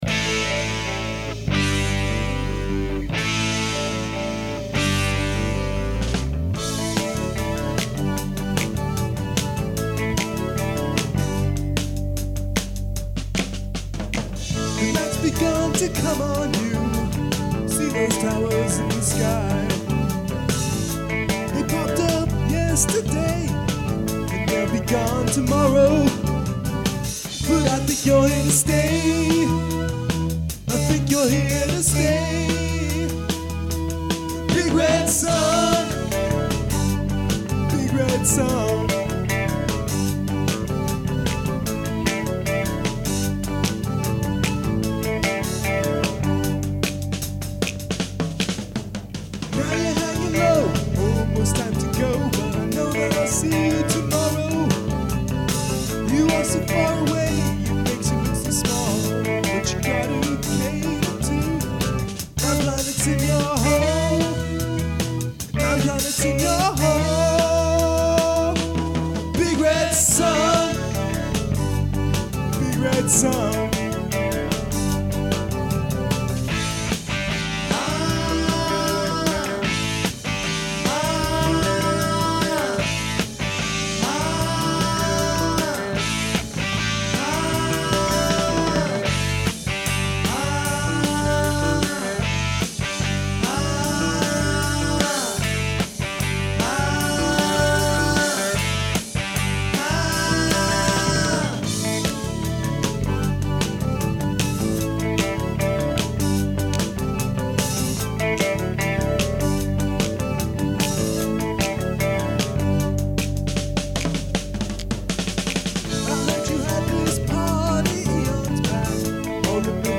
percussion
bass guitar
keyboards
guitar, vocals